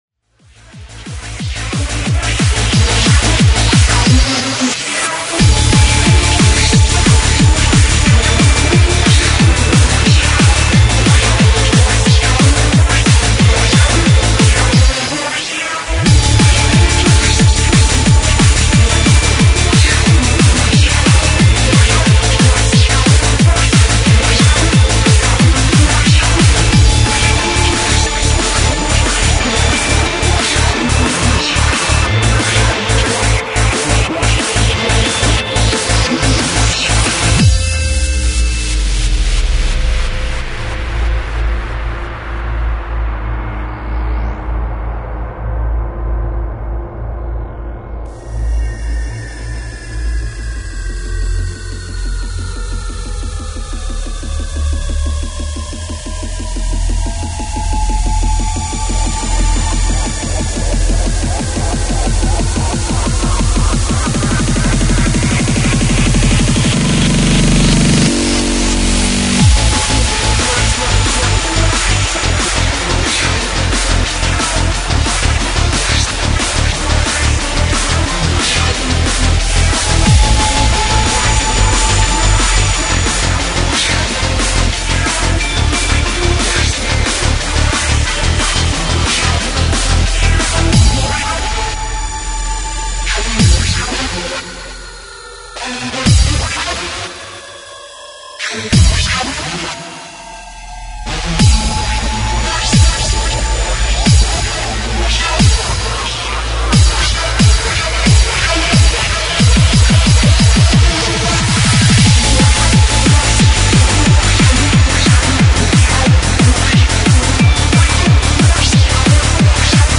Freeform/Finrg/Hardcore